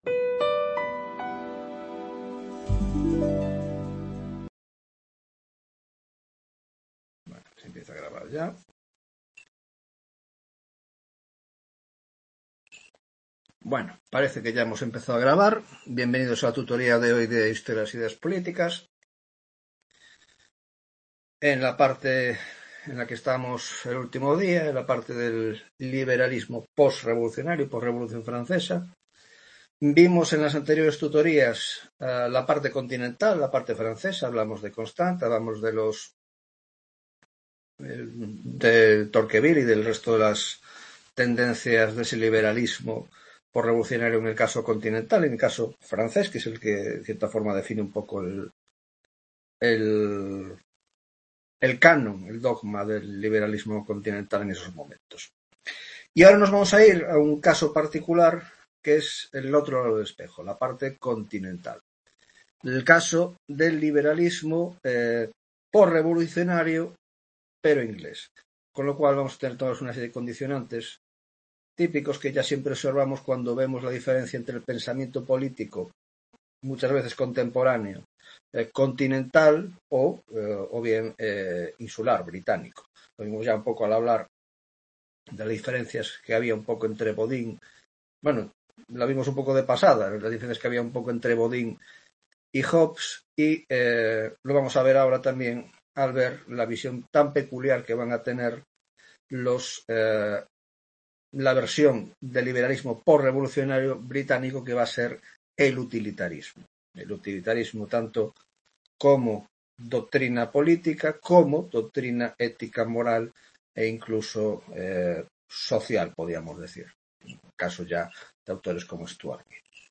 10ª Tutoría de Historia de las Ideas Políticas 2 (Grado de Ciencias Políticas)